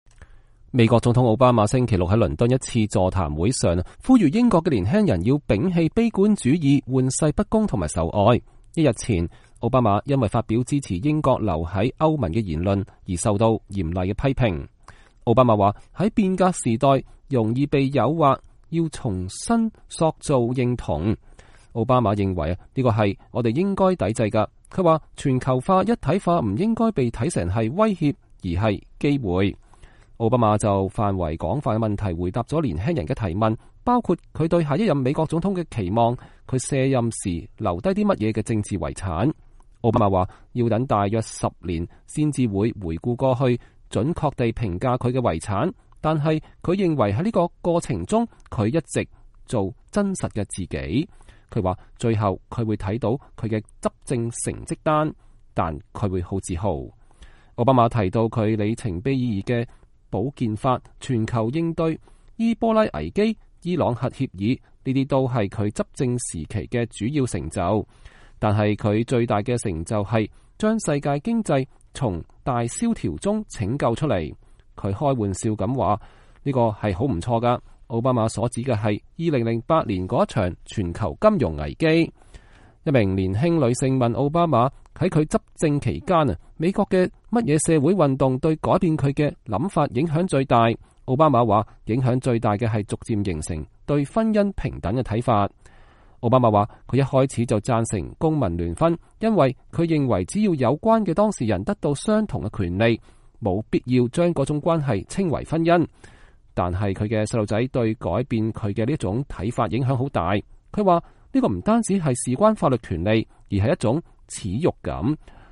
英國年輕人踴躍舉手向奧巴馬發問
美國總統奧巴馬星期六在倫敦的一次座談會上，呼籲英國年輕人要摒棄悲觀主義、玩世不恭和仇外。